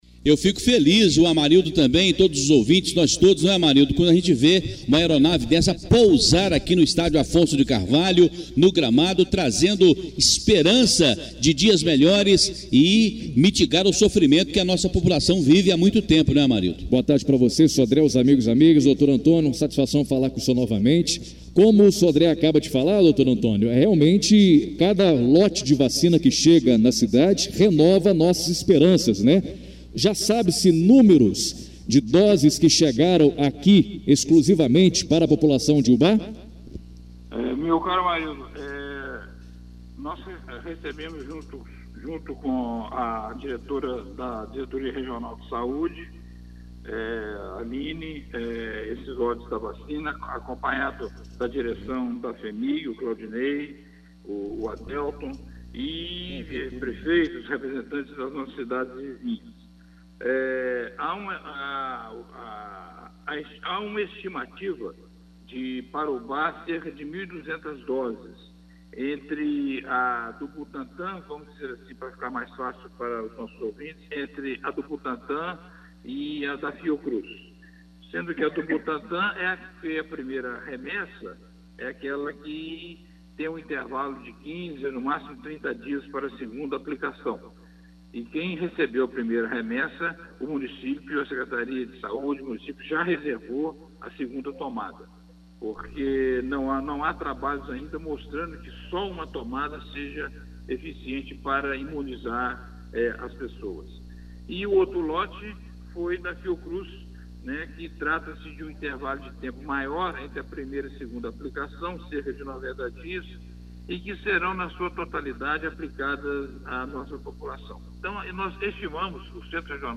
Em entrevista à Rádio Educadora AM/FM